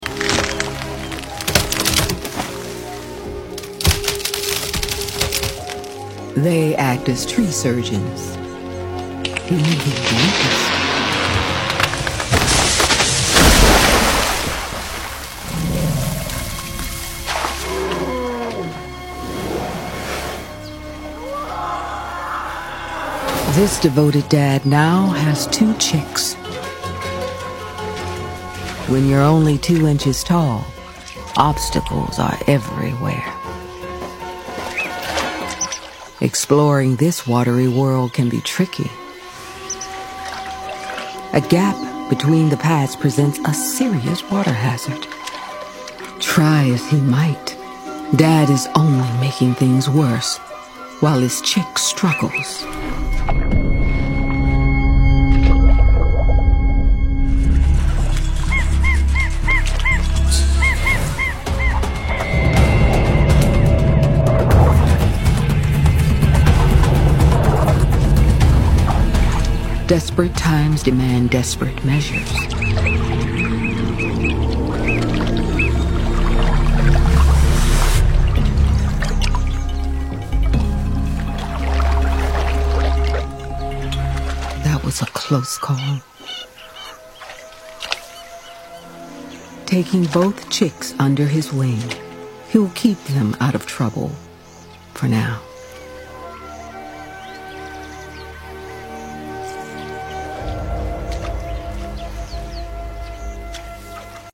African jacana.